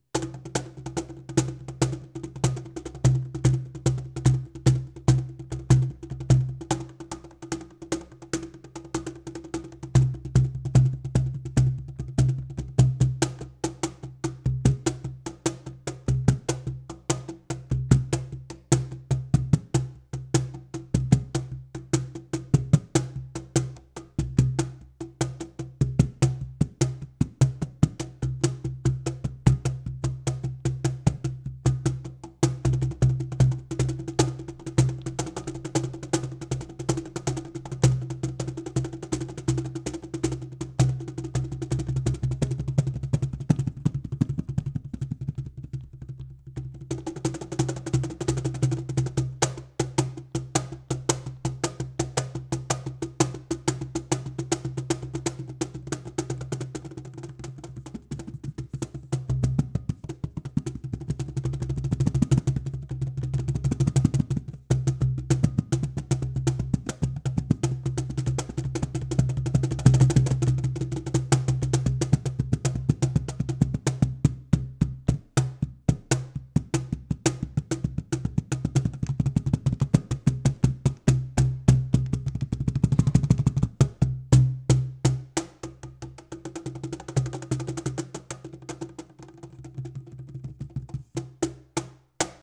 Cajon
Front placement clip
As you can hear there are more mids and highs allowing you to hear the sound of the surface and the snares vibrating. (Provided that the Cajon you are micing has snares in it.)
cajon-sm57-front.m4a